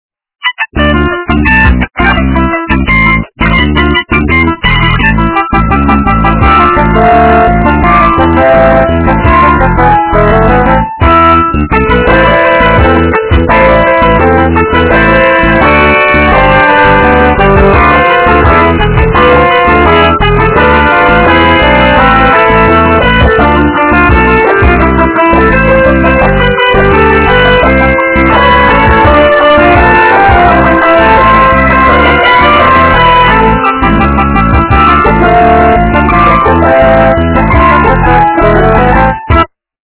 - фильмы, мультфильмы и телепередачи
качество понижено и присутствуют гудки